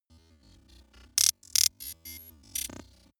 Gemafreie Sounds: Digital